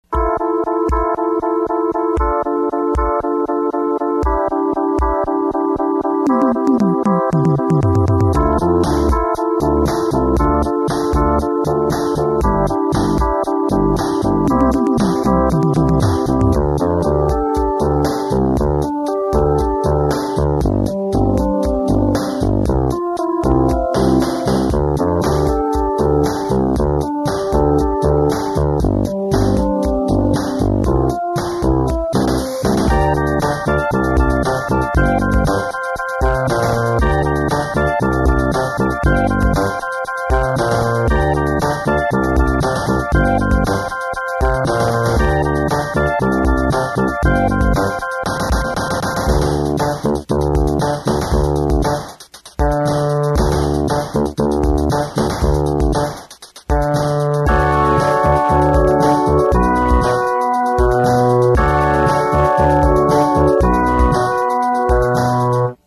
Here are some recordings of IMS music. The sound quality varies greatly as they are all from old cassette tapes, some of which were recorded with the input level too high...